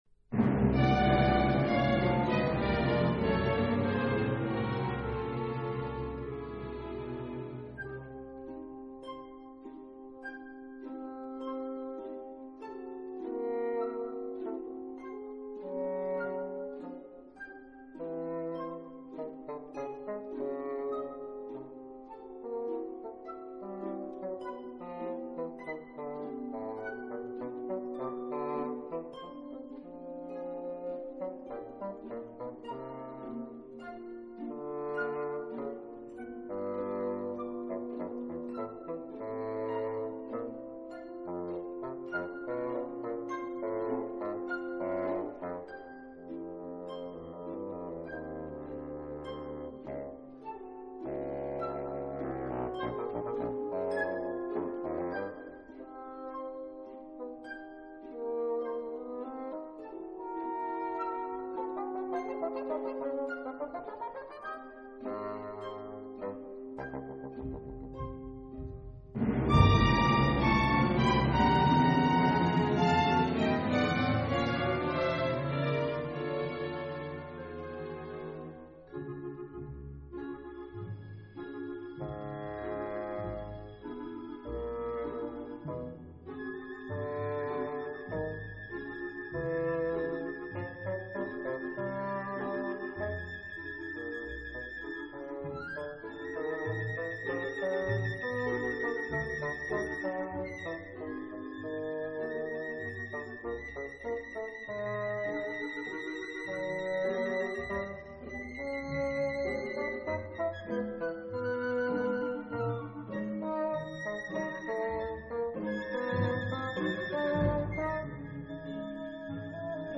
in the concerto, the piccolo dances with the solo bassoon